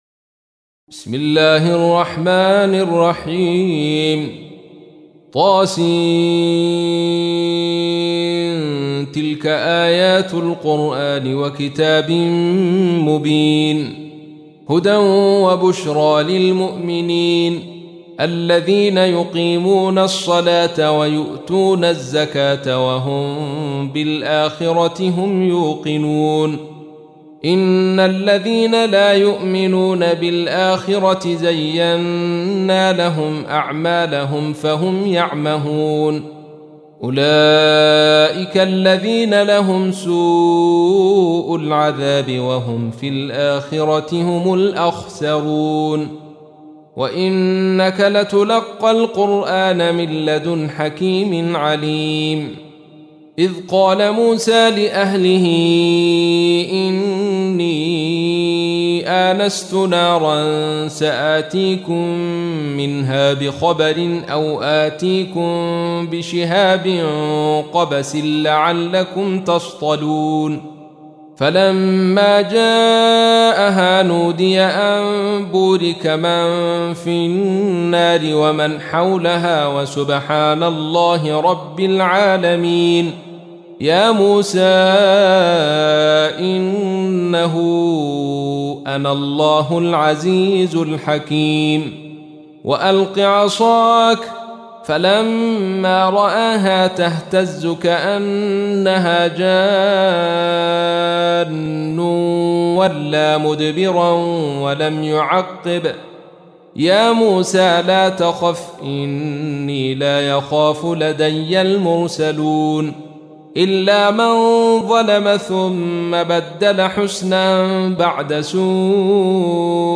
تحميل : 27. سورة النمل / القارئ عبد الرشيد صوفي / القرآن الكريم / موقع يا حسين